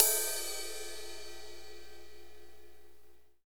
CYM A C 1819.wav